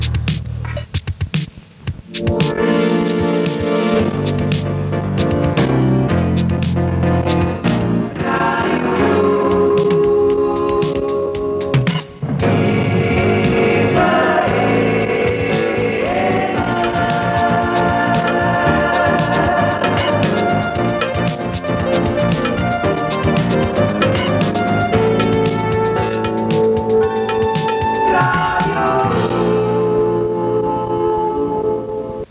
Indicatiu de l 'emissora